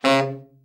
TENOR SN   6.wav